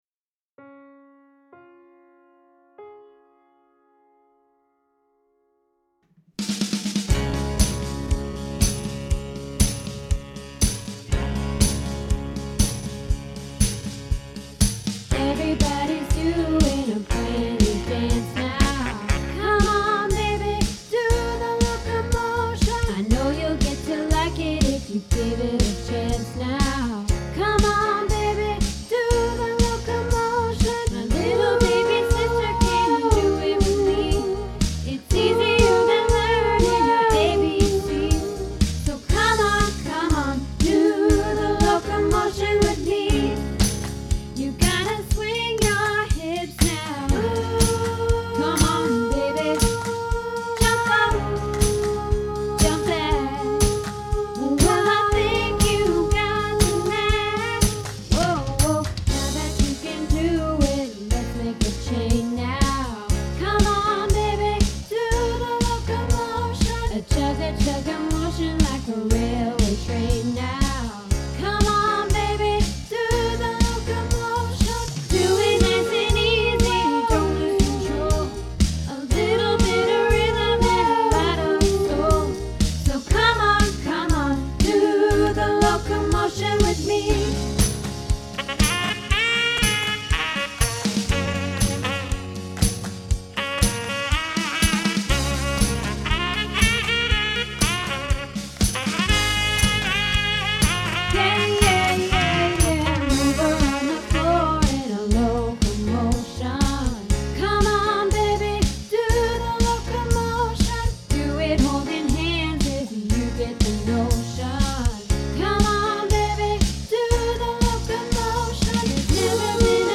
Locomotion - Soprano